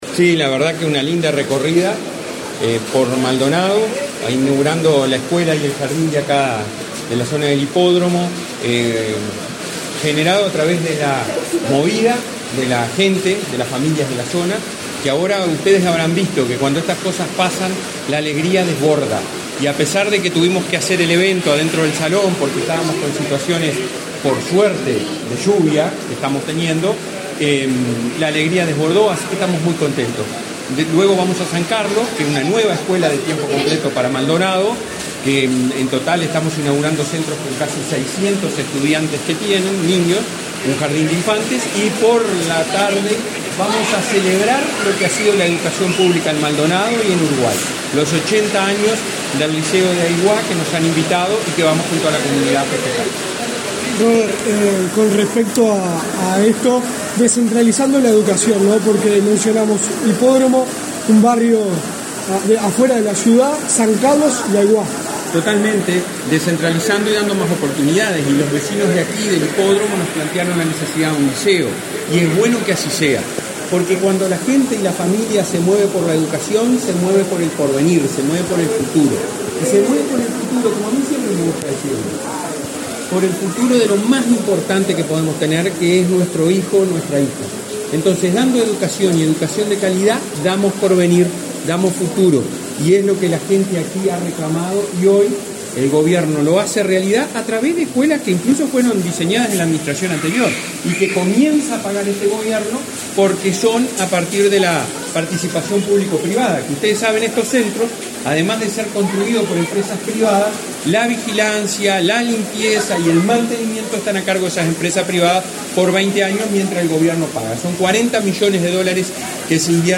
Declaraciones a la prensa del presidente de la ANEP, Robert Silva
Declaraciones a la prensa del presidente de la ANEP, Robert Silva 26/05/2023 Compartir Facebook Twitter Copiar enlace WhatsApp LinkedIn La Administración Nacional de Educación Pública (ANEP), a través de la Dirección General de Educación Inicial y Primaria, inauguró, este 26 de mayo, el jardín de infantes n.º 110 de jornada completa y la escuela n.º 111, en Maldonado. Tras el evento, Silva realizó declaraciones a la prensa.